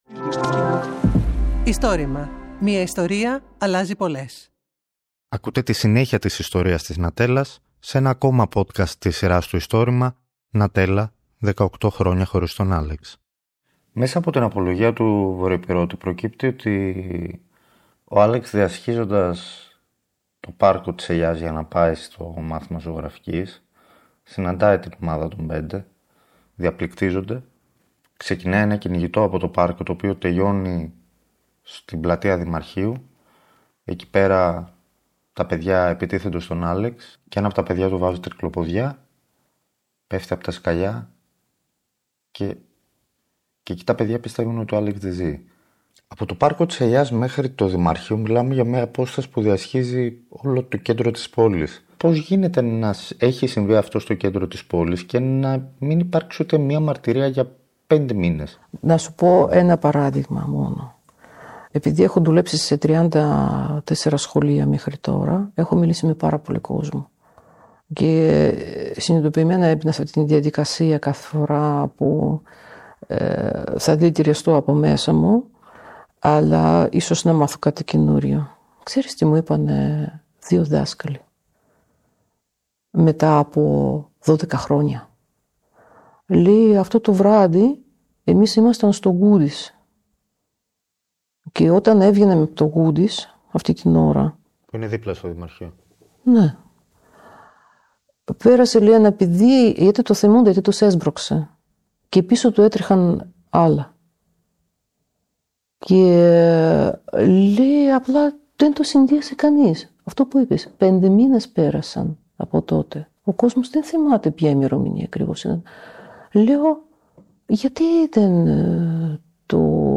Αφήγηση